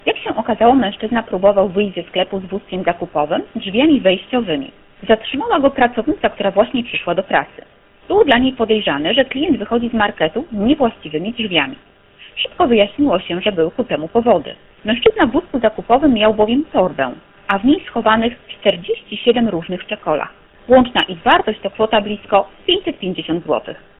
Mówiła